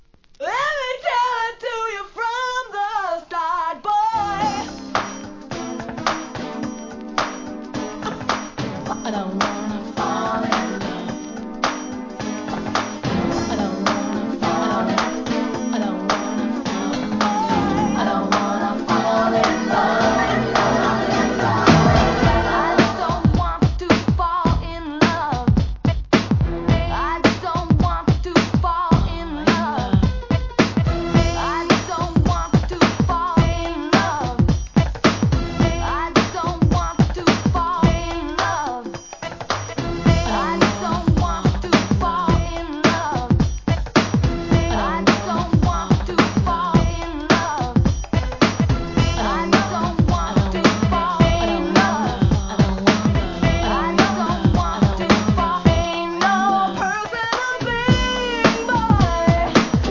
HIP HOP/R&B
NEW JACK SWING